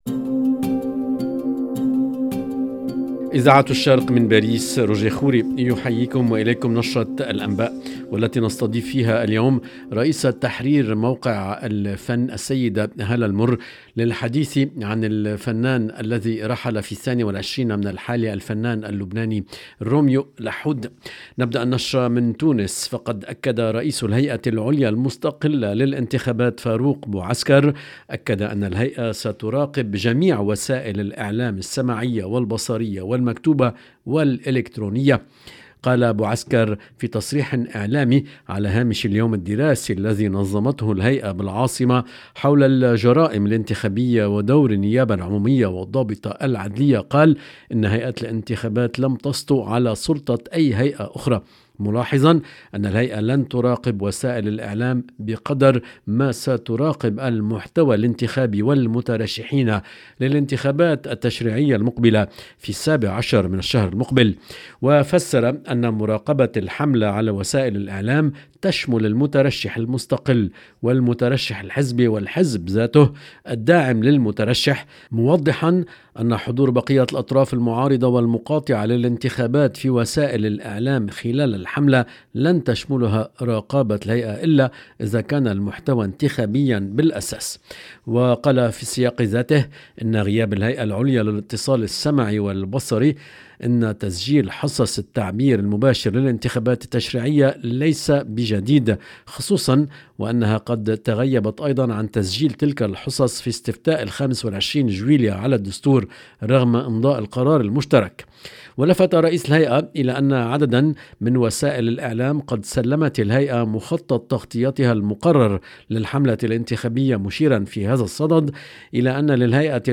LE JOURNAL EN LANGUE ARABE DU SOIR DU 24/11/22